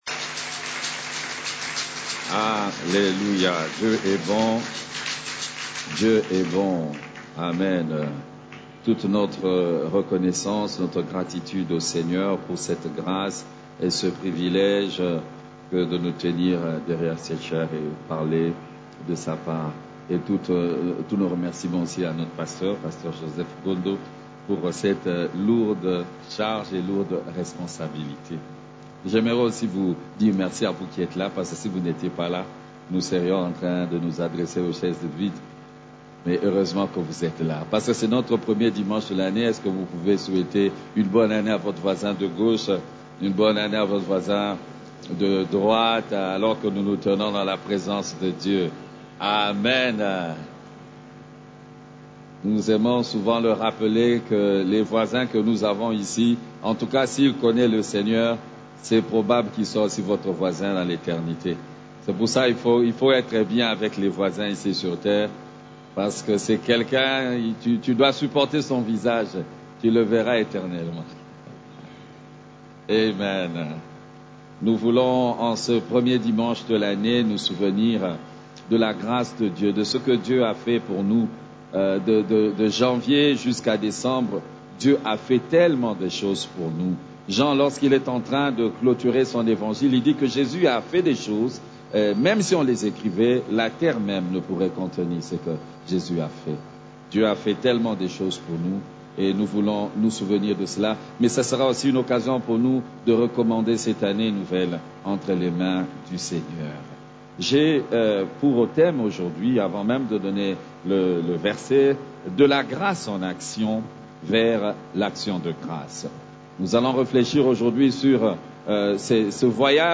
CEF la Borne, Culte du Dimanche, De la grâce en action vers l'action de grâce